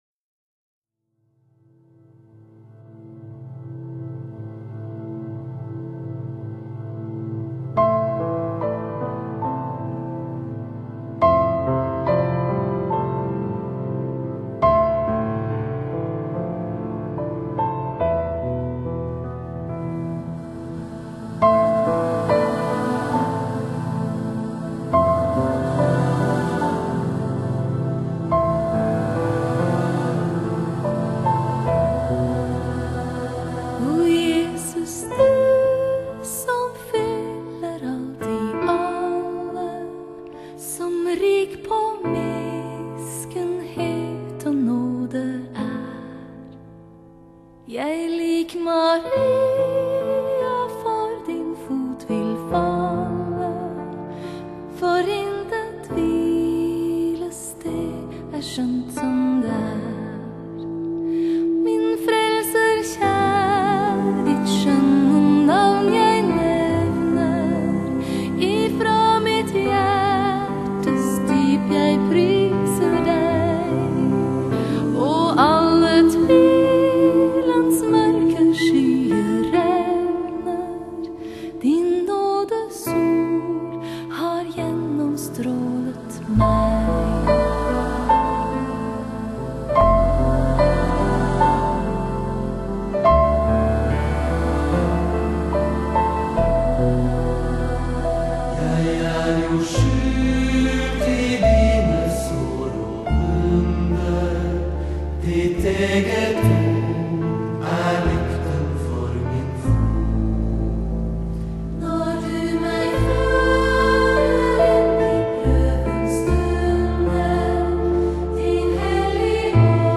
這張專輯無論是在音色、音質、層次、空間，乃至於音場定位都突破了舊有CD的音質，發燒效果更是向前邁進了一大步。